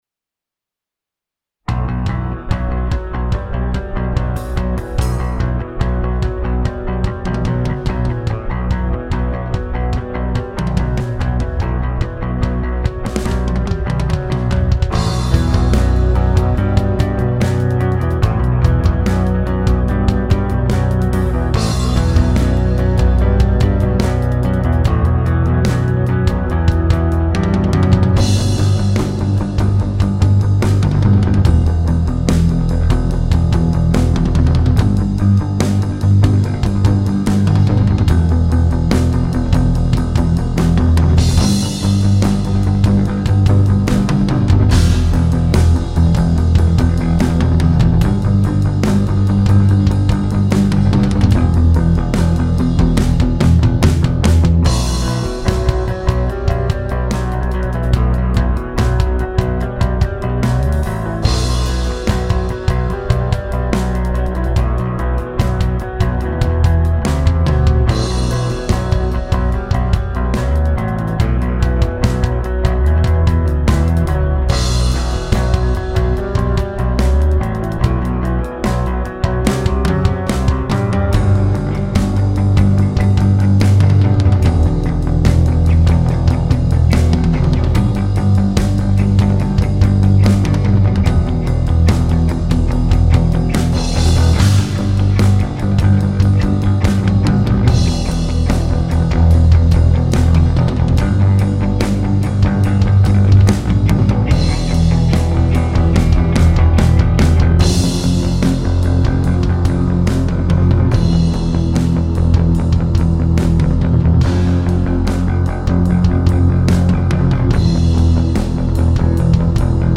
Any sugestions on what could be improved would be greatly appreciated! besides the lack of vocals hahah.